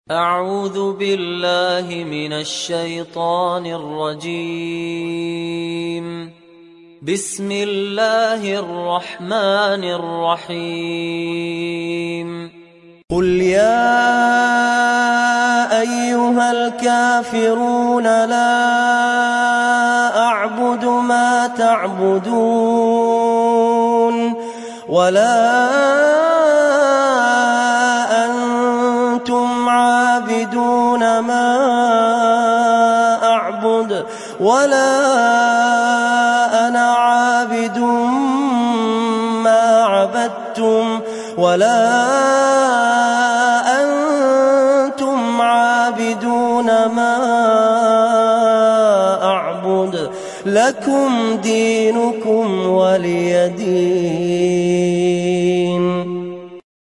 تحميل سورة الكافرون mp3 بصوت فهد الكندري برواية حفص عن عاصم, تحميل استماع القرآن الكريم على الجوال mp3 كاملا بروابط مباشرة وسريعة